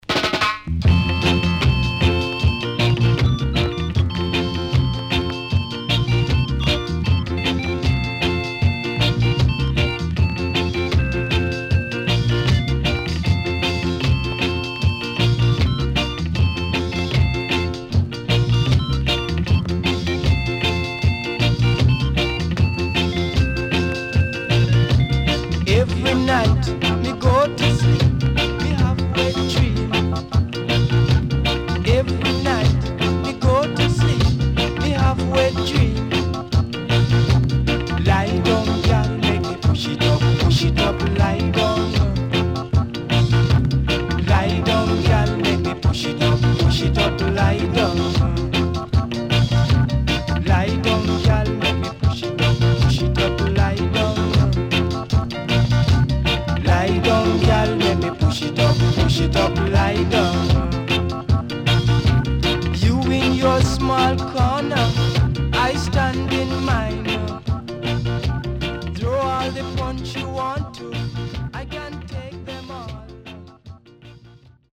CONDITION SIDE A:VG(OK)〜VG+
SIDE A:少しチリノイズ入りますが良好です。